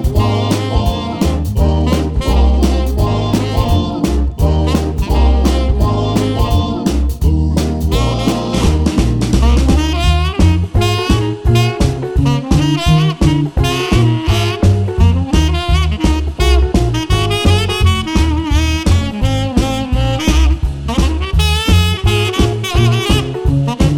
Two Semitones Down Rock 'n' Roll 2:21 Buy £1.50